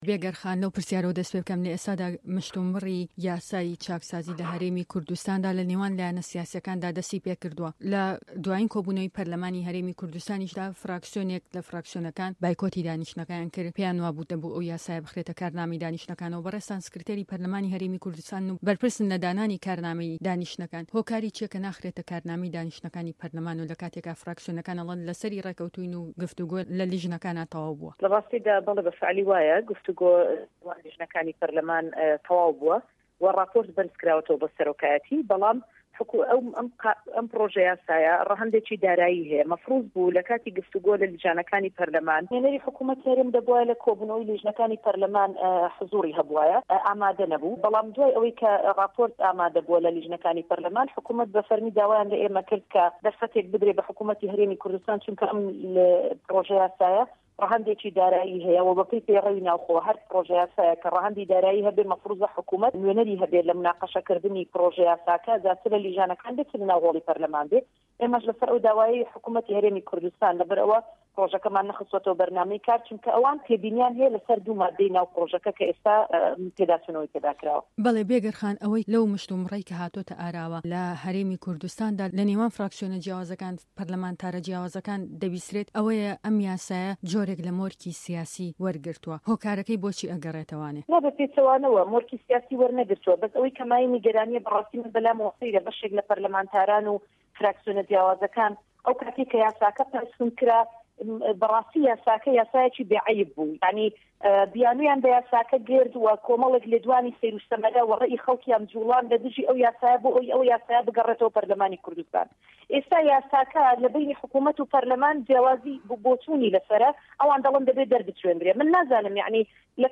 ده‌قی وتوێژه‌كه‌ی